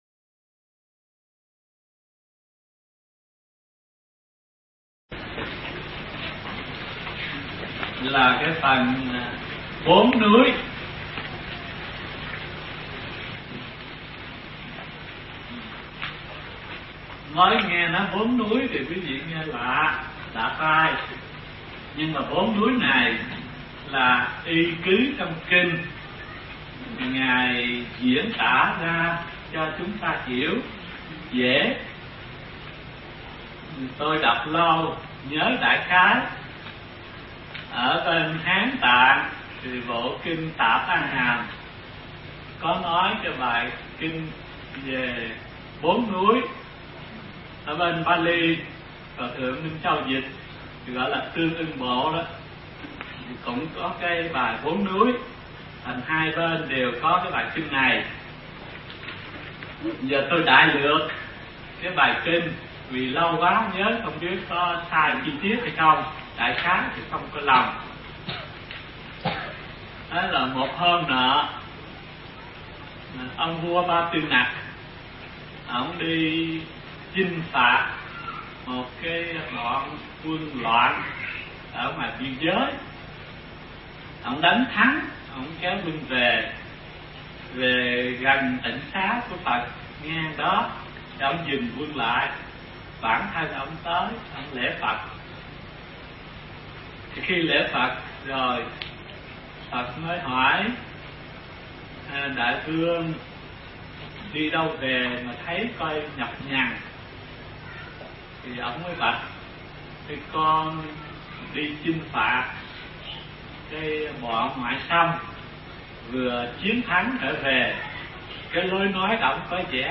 Tải mp3 Thuyết Pháp Khóa Hư Lục 04 – Bốn Núi – Hòa Thượng Thích Thanh Từ giảng tại thiền viện Trúc Lâm (Đà Lạt) năm 1994